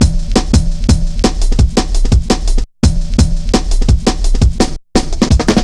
Index of /90_sSampleCDs/Zero-G - Total Drum Bass/Drumloops - 3/track 55 (170bpm)